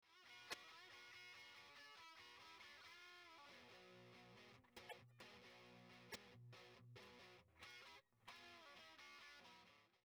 いわゆるカットオフトーンコントロールではなく、絞り込んだ場合にも埋もれることなくハイを残し、まろやかで太い低音を出力。